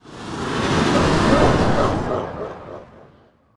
demondeath.ogg